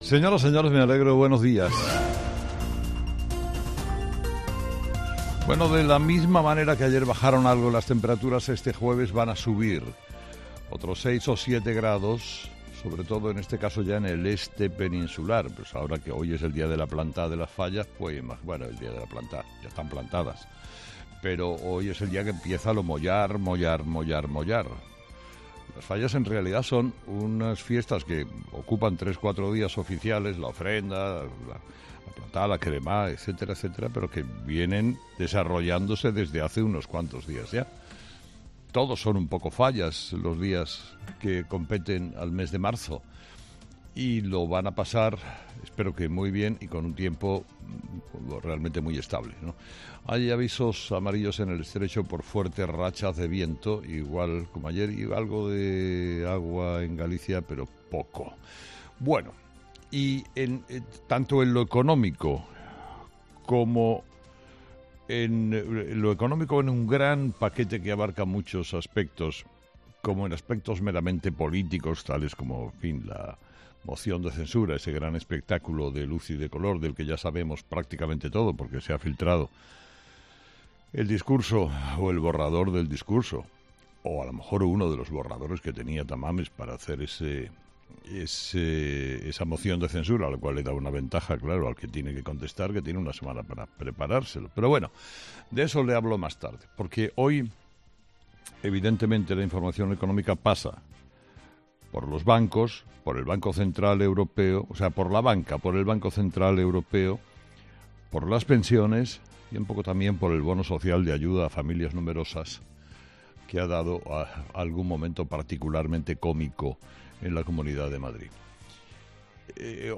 Carlos Herrera repasa los principales titulares que marcarán la actualidad de este jueves 16 de marzo en nuestro país